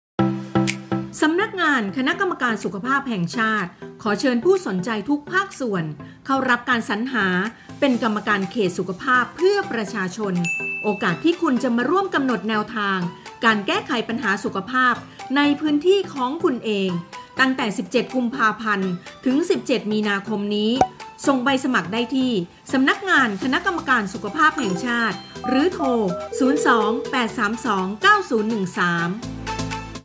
สปอตวิทยุ การสรรหา กขป. 2568 | สำนักงานคณะกรรมการสุขภาพแห่งชาติ